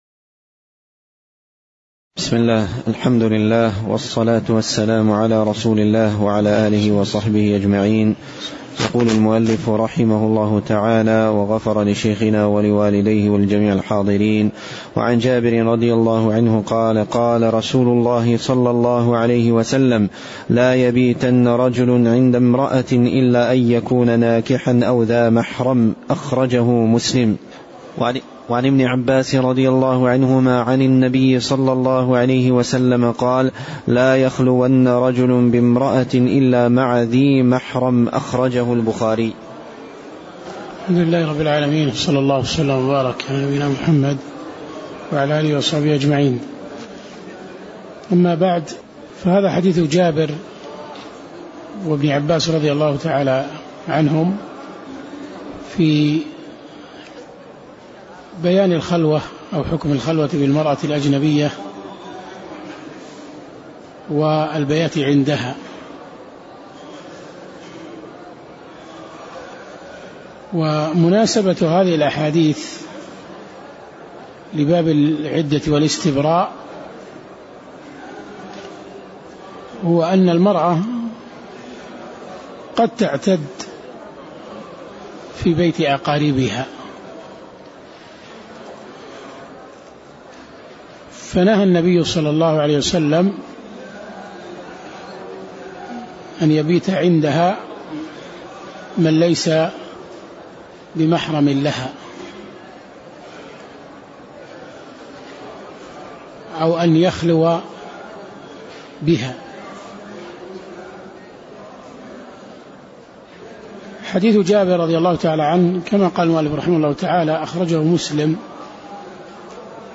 تاريخ النشر ١١ صفر ١٤٣٩ هـ المكان: المسجد النبوي الشيخ